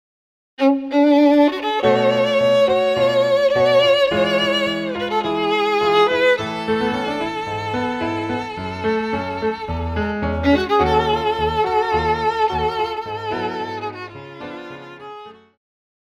古典,流行
鋼琴
演奏曲
世界音樂
僅伴奏
沒有主奏
沒有節拍器